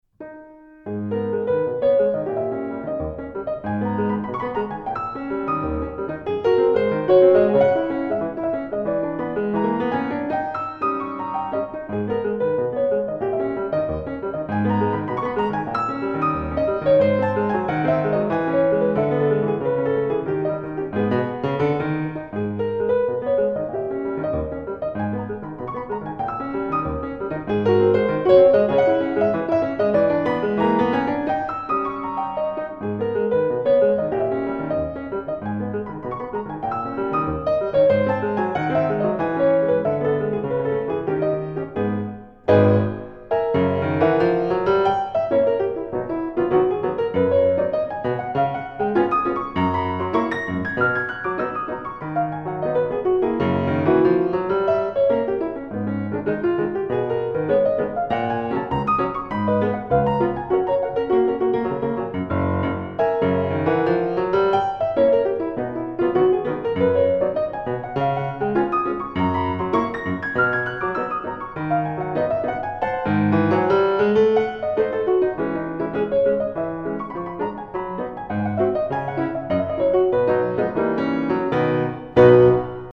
Plusieurs Cds  piano solo où domine l'improvisation: